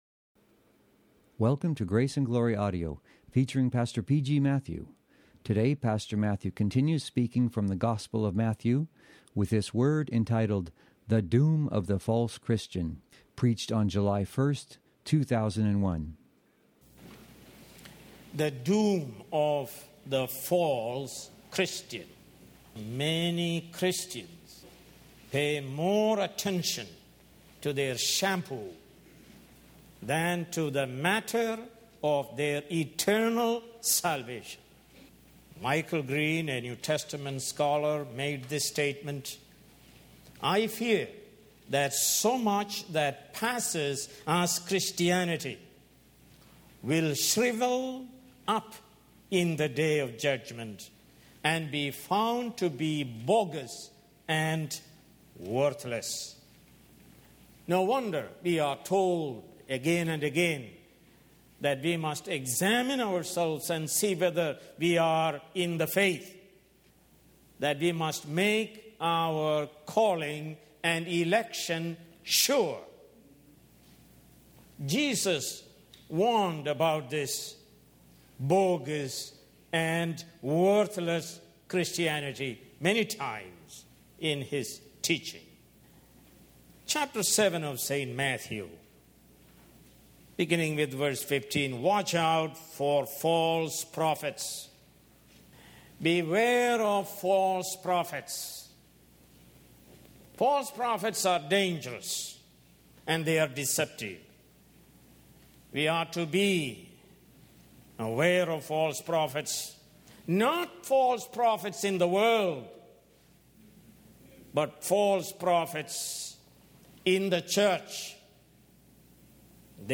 More Sermons From the book of Matthew